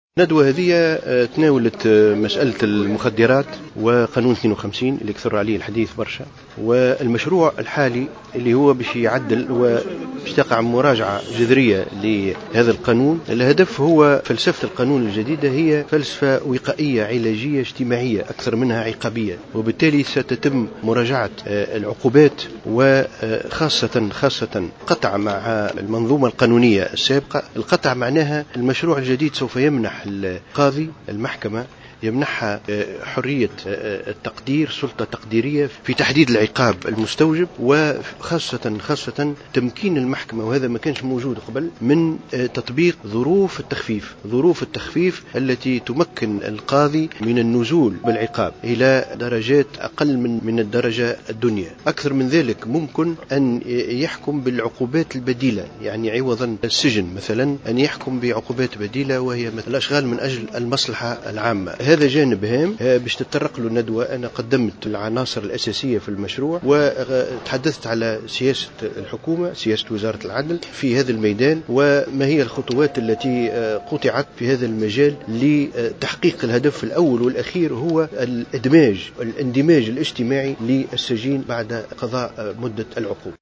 وقال وزير العدل في تصريح لمراسلة الجوهرة أف أم إن مشروع مراجعة القانون عدد 52 الخاص بتعاطي المخدرات سيقوم على فلسفة وقائية علاجية اجتماعية بدلا من العقابية.